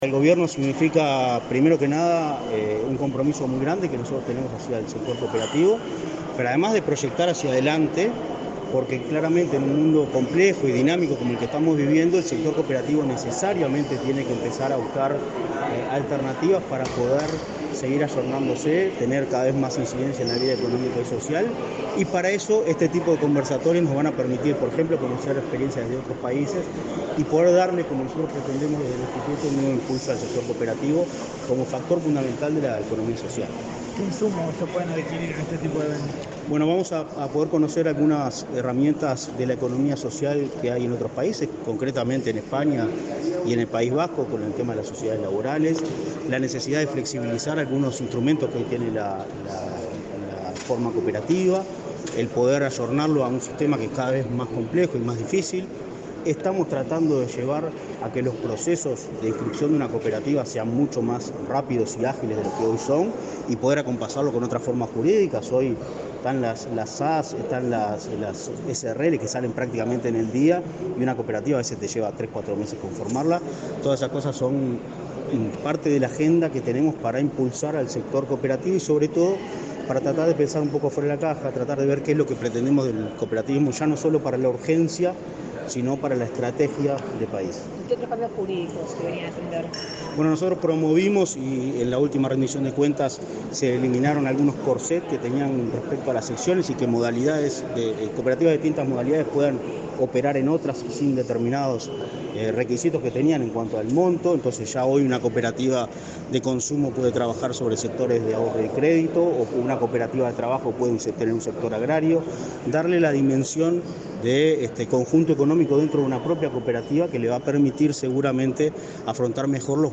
Declaraciones del presidente del Instituto Nacional del Cooperativismo, Martín Fernández
Antes, dialogó con la prensa.